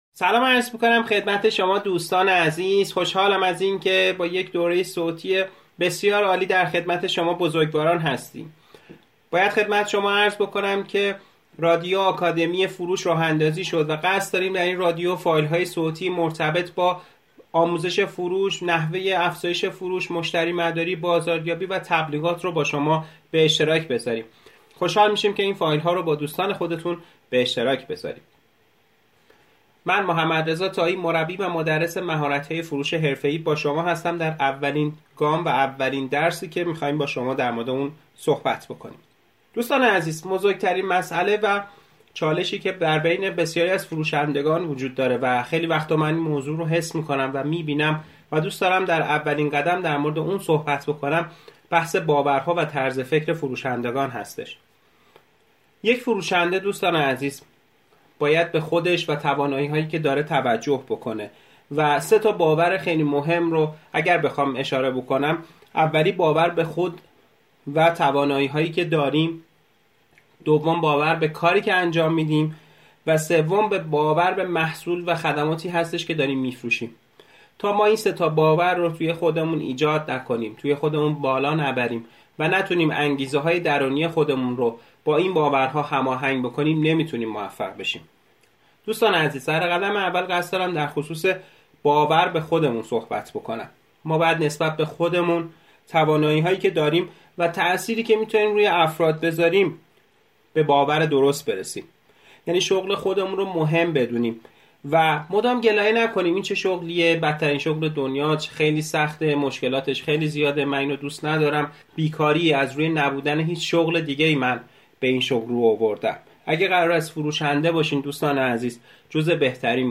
رادیو آکادمی فروش(درس اول) باورهای فروشندگان